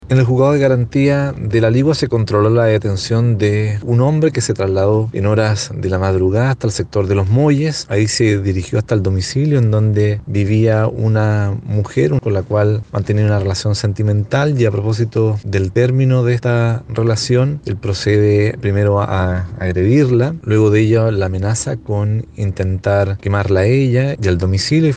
Así detalló los hechos el fiscal Jefe de La Ligua, Luis Cortez.